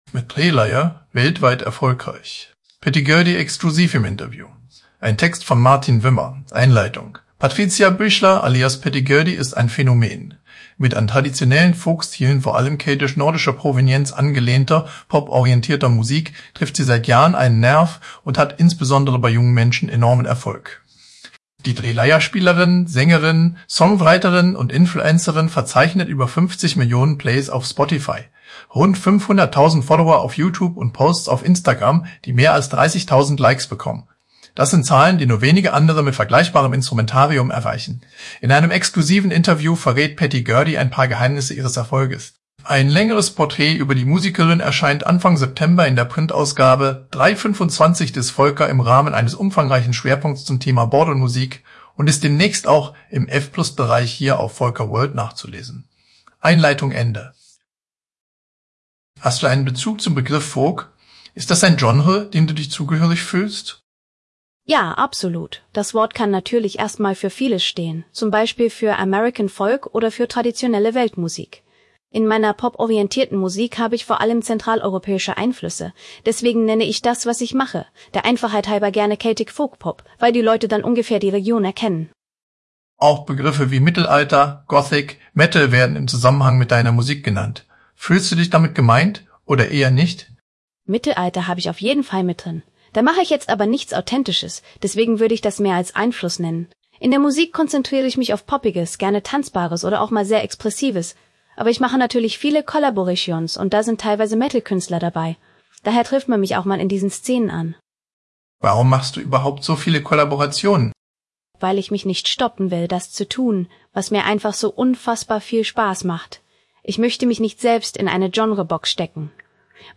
Die Stimmen dieser Lesung wurden freundlicherweise zur Verfügung gestellt und dürfen ohne ausdrückliche Genehmigung nicht weiterverwendet werden.